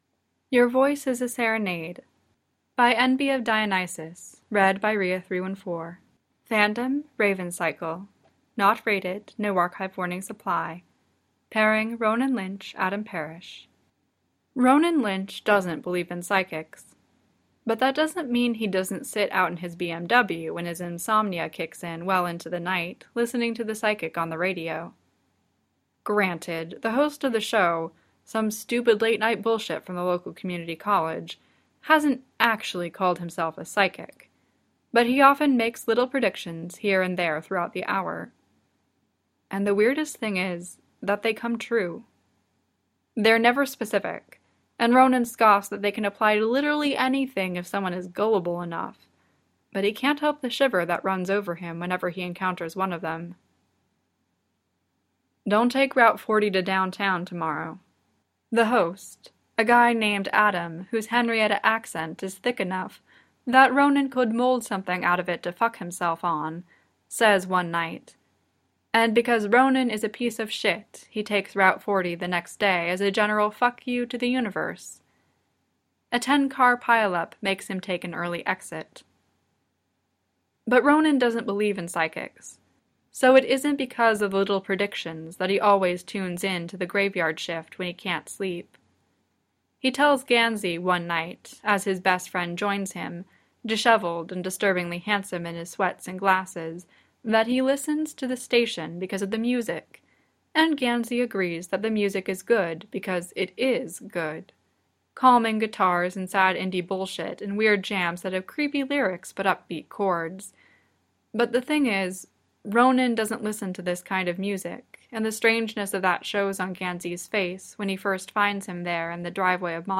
two voices